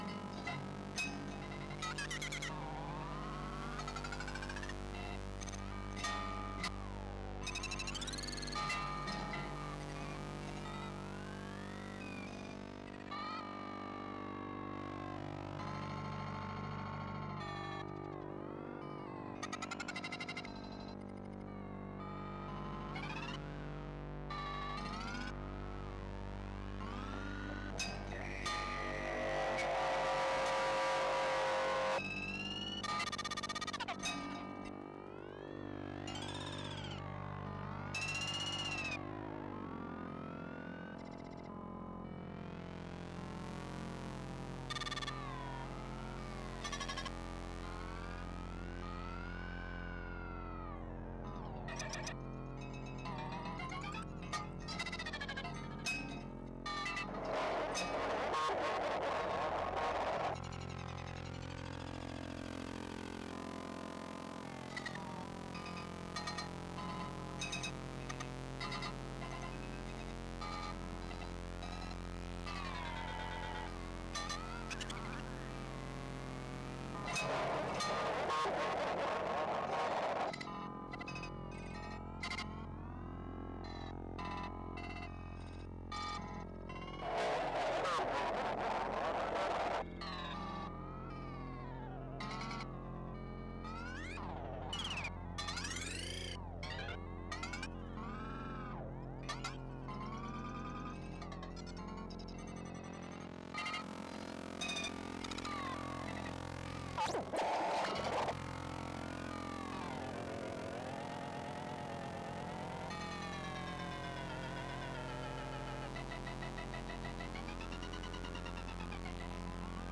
audiovisual improvisations
Highly sensitive pickups capture microscopic vibrations of kinetic sculptures and translate them into fragile, raw sonic material. Rotating objects set steel strings into motion; each plucked string acts as an impulse generator: it controls shadow-casting light and digital image processes, triggers synthetic sounds and their modulation. Light sensors convert signals into fragmented rhythms of a toy piano.